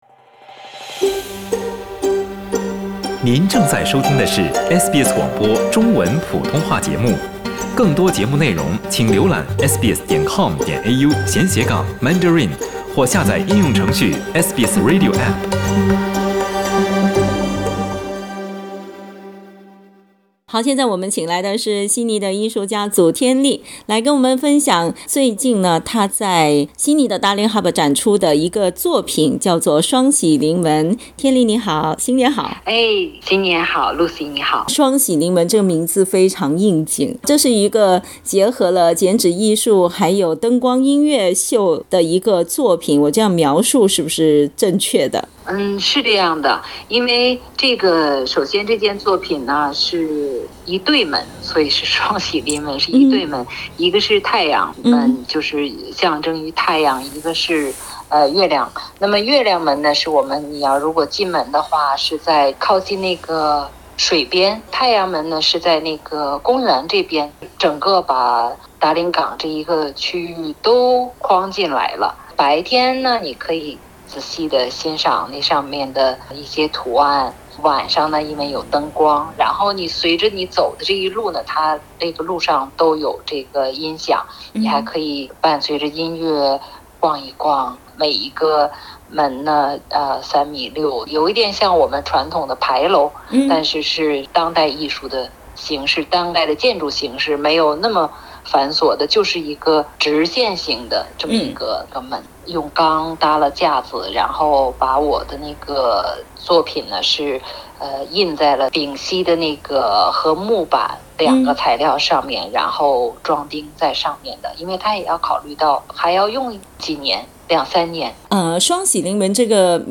春节期间，游客们可以看到那里有一个太阳门，一个月亮门。（点击图片收听报道）